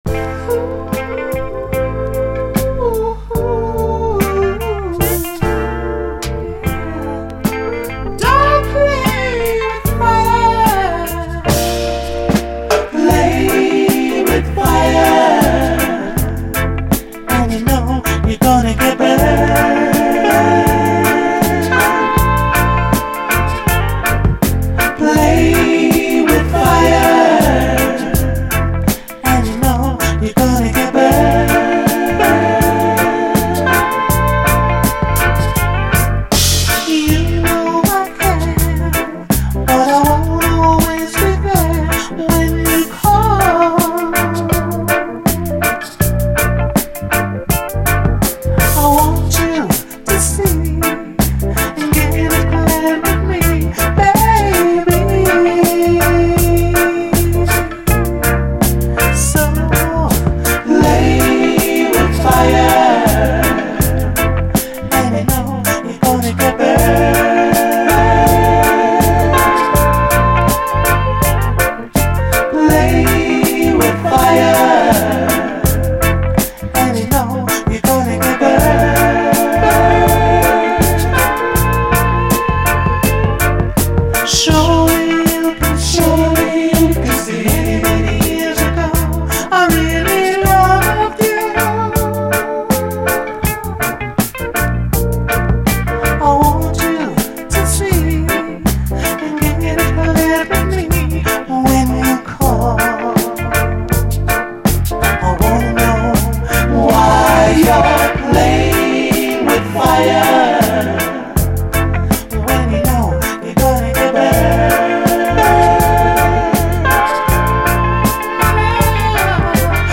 REGGAE
最高スウィート・ジャマイカン・ソウル〜UKラヴァーズ！甘茶ソウルのようなトロトロのファルセット＆コーラス！
「ダブ」